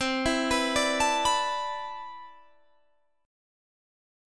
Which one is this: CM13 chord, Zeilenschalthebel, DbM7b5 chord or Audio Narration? CM13 chord